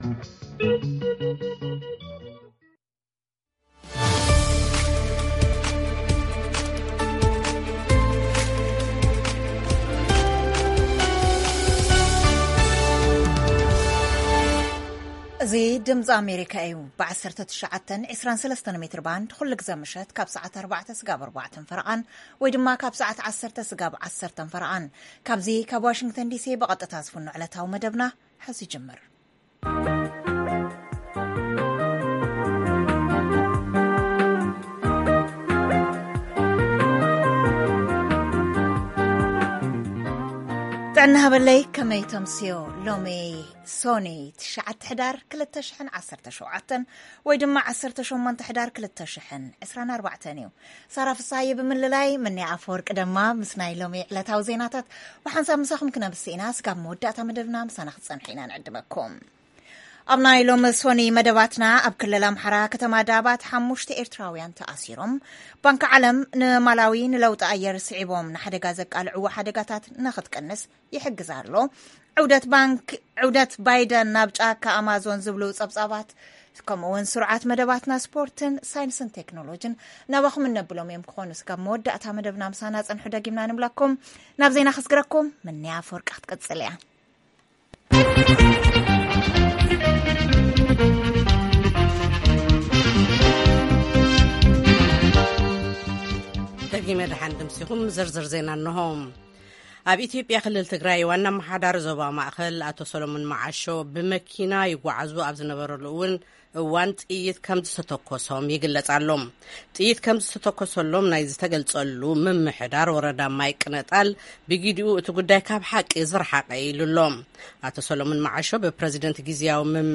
ፈነወ ቋንቋ ትግርኛ ድምጺ ኣመሪካ ዞባዊን ኣህጉራዊ ዜና መደብ ስፖርትን መደብ ሳይንስን ቴክኖሎጂን